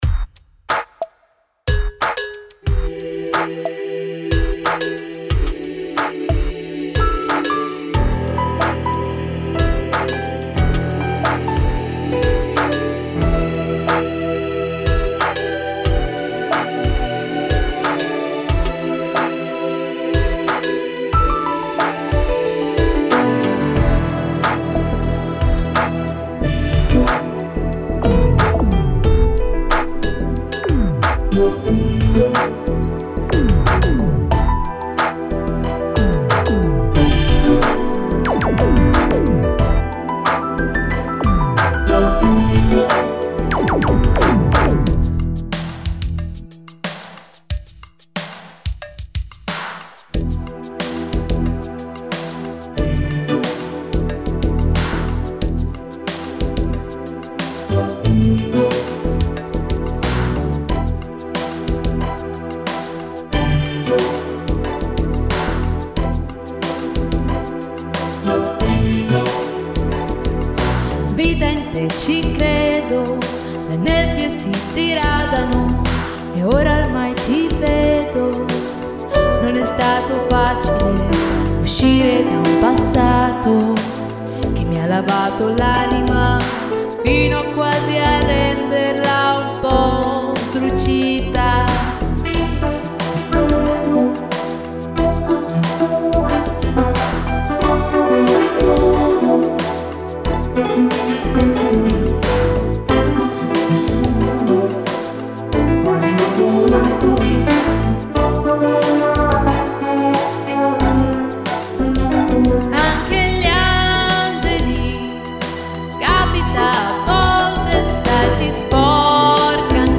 Synth: Yamaha SY-85